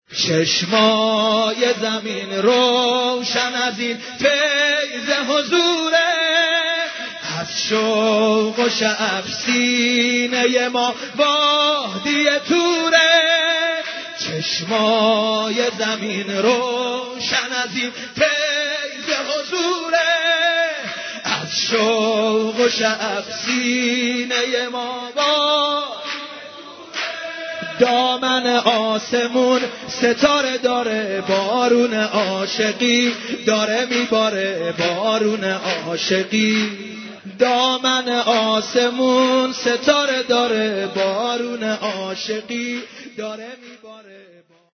زنگ خور موبایل مذهبی
به مناسبت میلاد حضرت معصومه